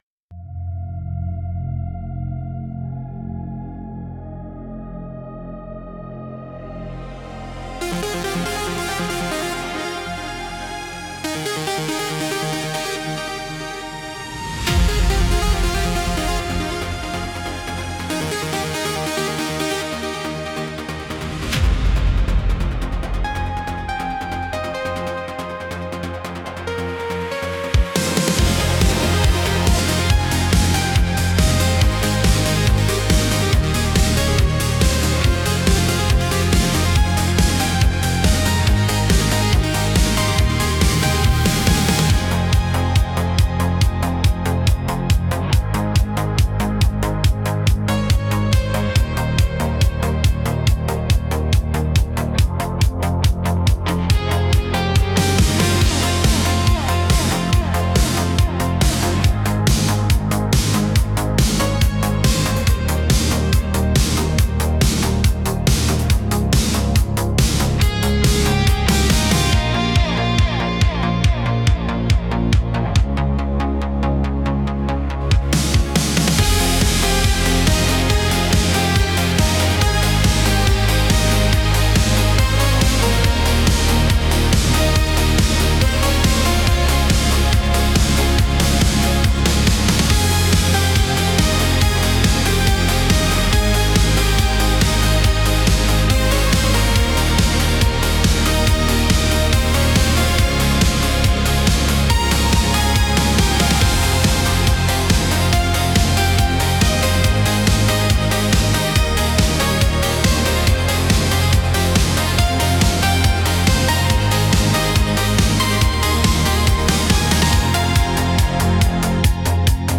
Instrumental - Ache of the Algorithm 3.59